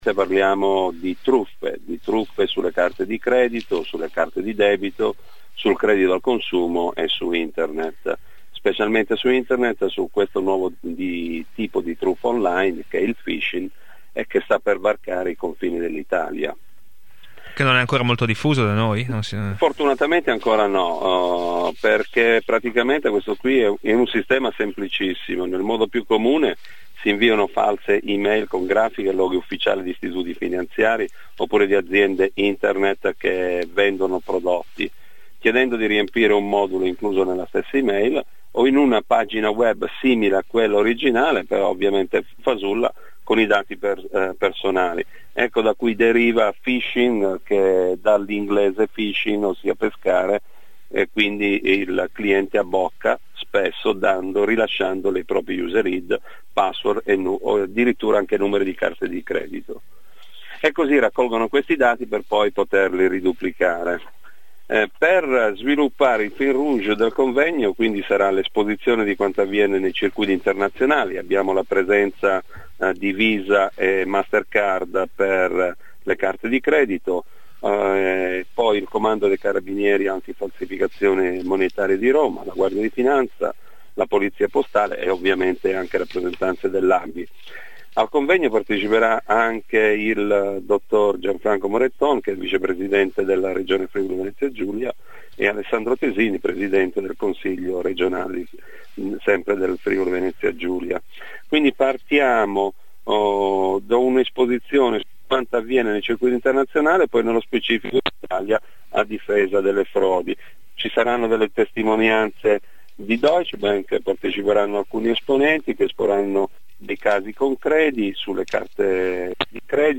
Intervista radiofonica